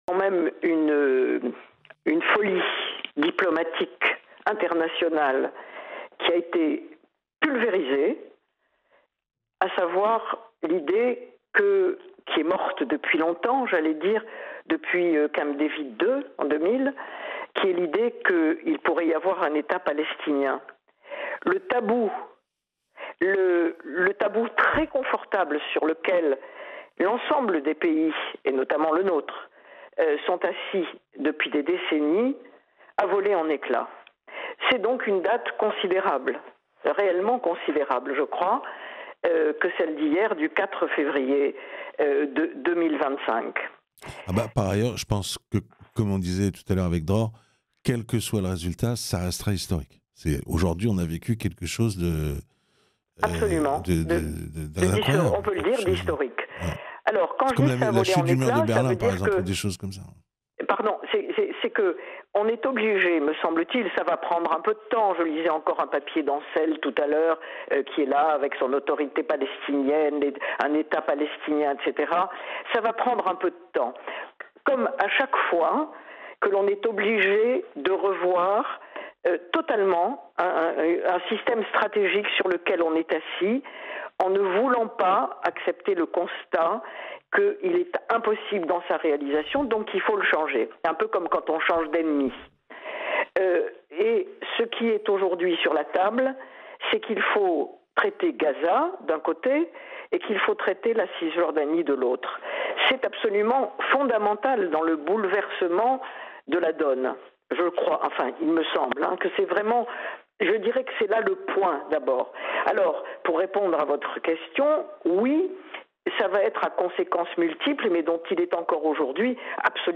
Elisabeth Schemla, journaliste est l'invitée de la rédaction de Radio Shalom.